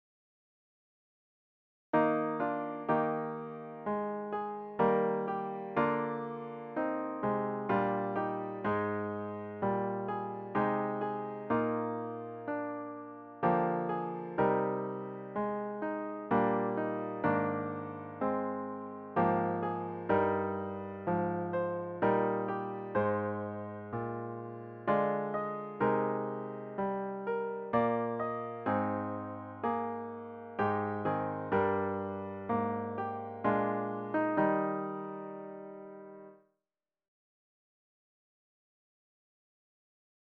The hymn should be performed at a unhurried♩= ca. 63.